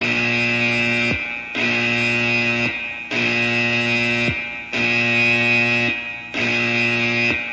Navy Alarm klingelton kostenlos
Kategorien: Soundeffekte